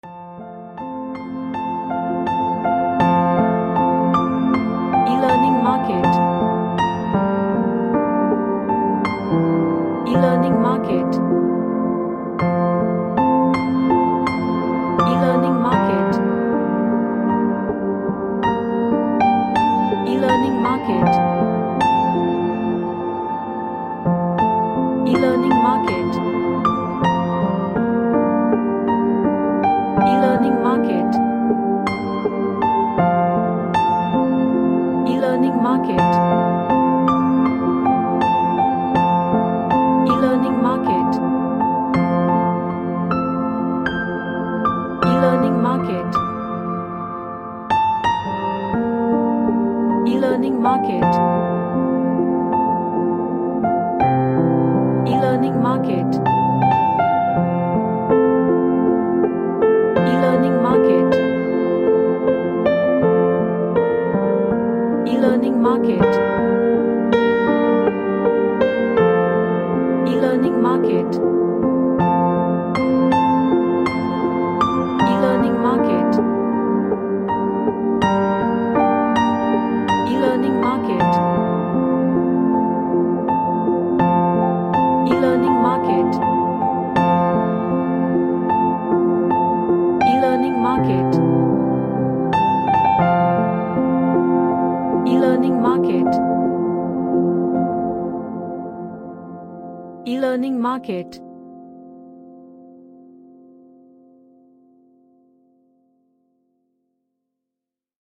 A meditative music
Relaxation / MeditationHope